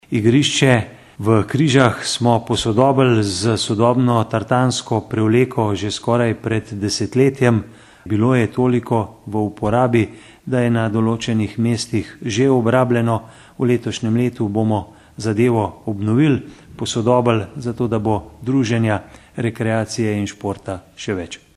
izjava_mag.borutsajoviczupanobcinetrzicoobnovitartanavsportnemparkukrize.mp3 (524kB)